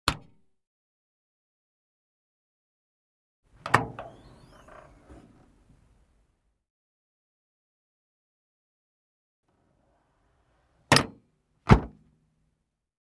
Звуки багажника